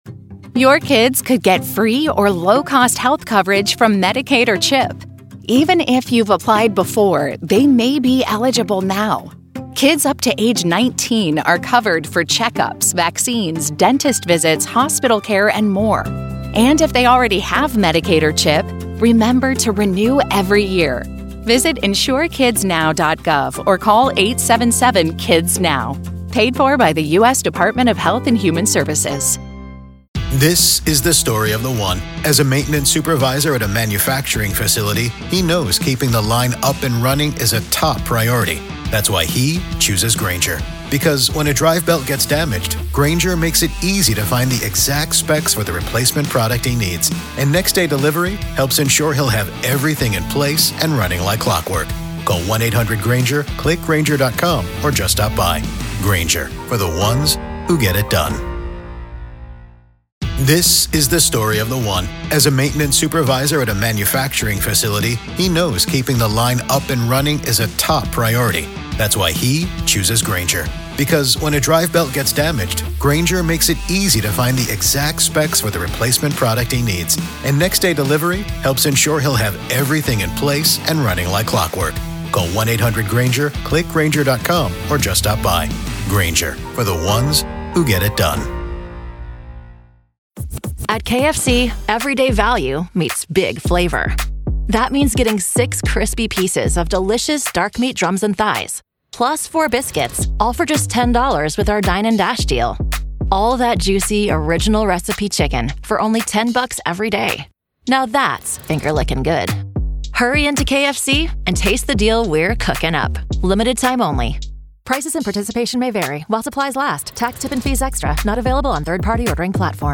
Welcome to a special episode of "The Trial of Karen Read," where today, we find ourselves inside the courtroom of the case against Karen Read. As we set the stage, let's briefly summarize the case that has gripped the public's attention.